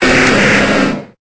Cri de Rhinocorne dans Pokémon Épée et Bouclier.